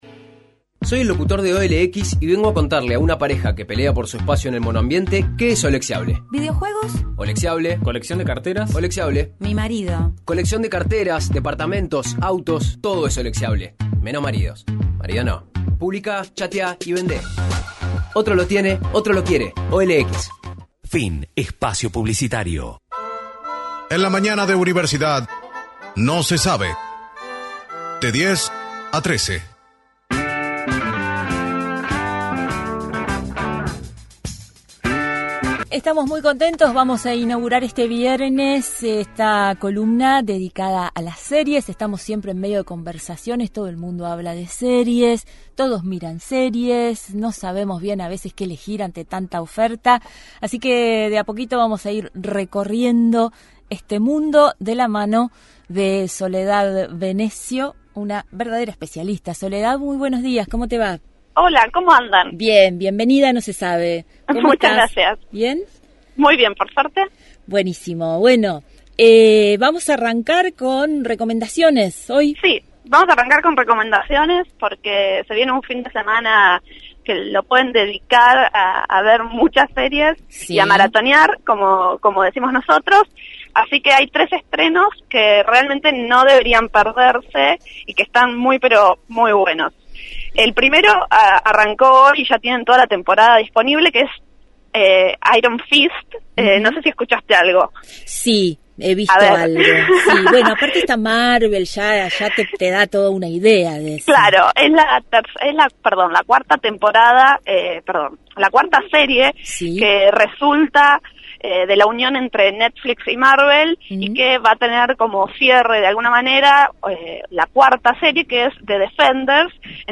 Columna sobre series